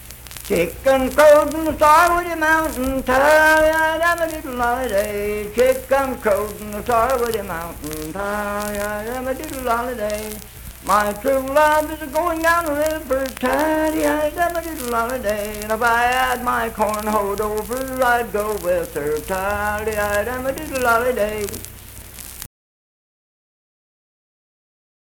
Unaccompanied vocal music performance
Children's Songs, Dance, Game, and Party Songs
Voice (sung)